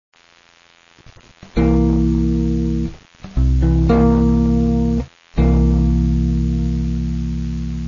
Si vous n'avez pas déjà capté..., en cliquant sur les tablatures, vous aurez l'illustration sonore (en C).
On utilise la main droite exactement de la même manière que sur une guitare acoustique: le pouce pour les notes graves, et l'index et majeur pour les notes aiguës sans buté mais simplement en pinçant.
accord7-1.WAV